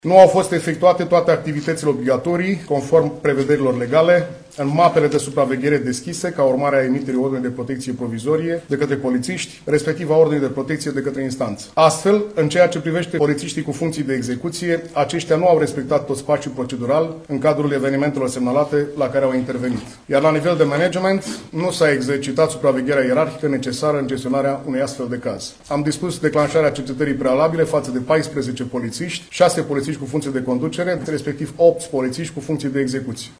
Șeful Poliției Române, Benone Matei: „Am dispus declanșarea cercetării prealabile față de 14 polițiști, șase polițiști cu funcții de conducere, respectiv opt polițiști cu funcții de execuție”